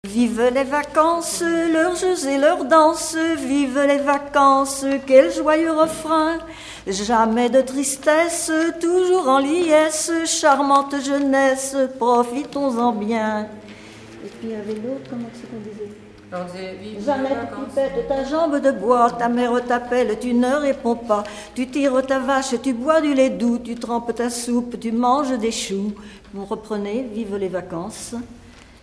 Thème : 1080 - L'enfance - Enfantines diverses
Catégorie Pièce musicale inédite